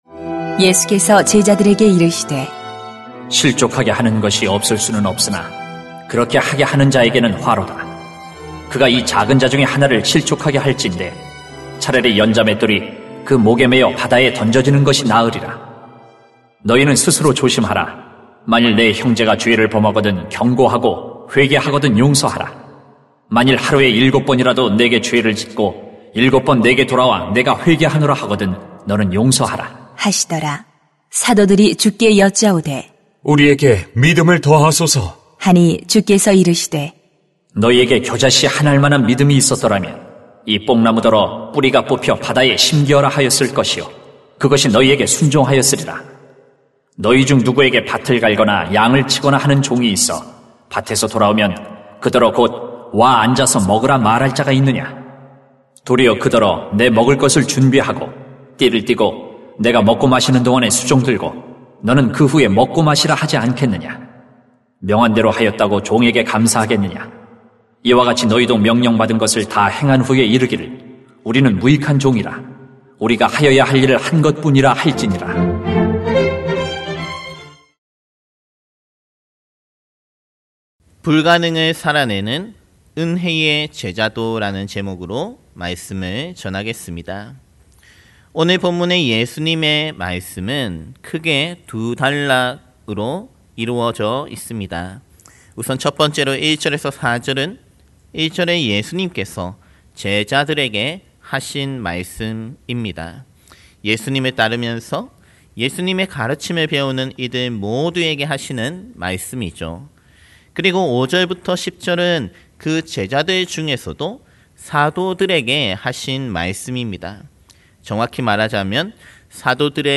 [눅 17:1-10] 불가능을 살아내는 은혜의 제자도 > 새벽기도회 | 전주제자교회